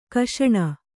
♪ kaṣaṇa